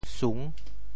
How to say the words 顺 in Teochew？
顺（順） Radical and Phonetic Radical 页 Total Number of Strokes 9 Number of Strokes 3 Mandarin Reading shùn TeoChew Phonetic TeoThew sung6 文 Chinese Definitions 顺 <动> (会意。
sung6.mp3